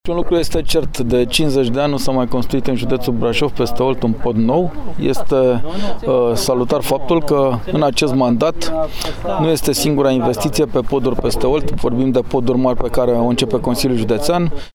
Preşedintele Consiliului Judeţean Braşov, Adrian Veştea: